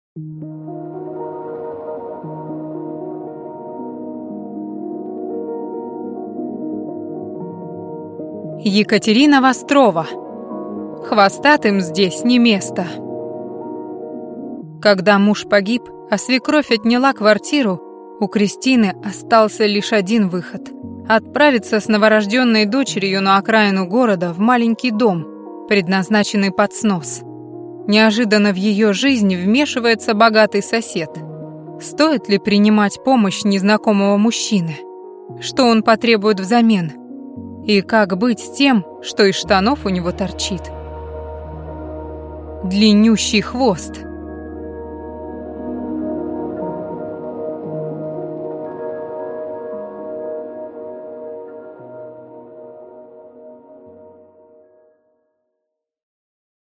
Аудиокнига Хвостатым здесь не место!